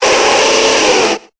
Cri de Feunard dans Pokémon Épée et Bouclier.